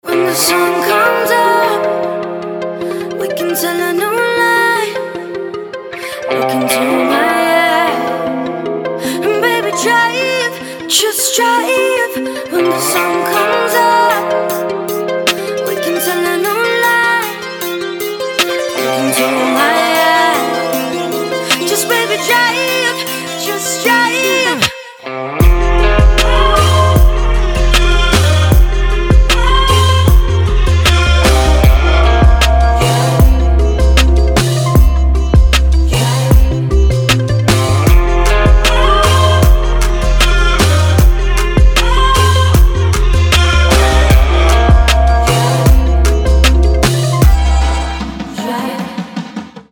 • Качество: 320, Stereo
красивые
женский вокал
грустные
dance
Electronic
Downtempo